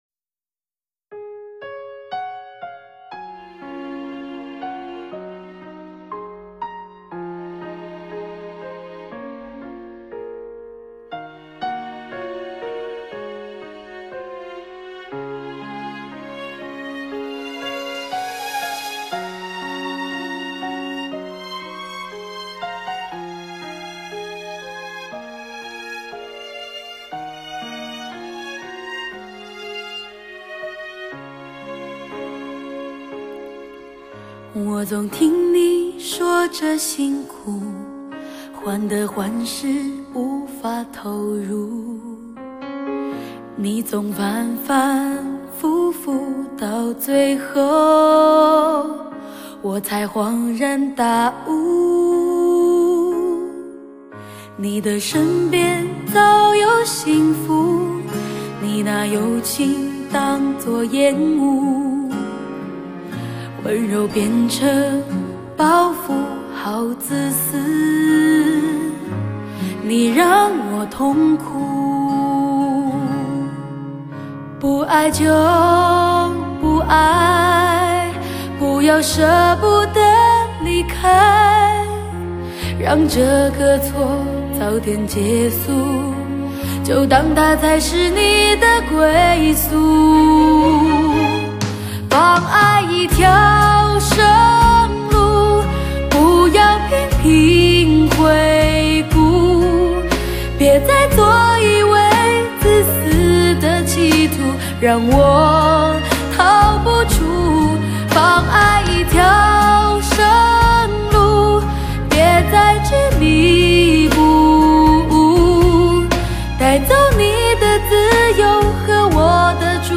陪孤独作伴的声音里透射出欢愉动听的歌声。
平静、淡定中触觉情愫索挚的撞击。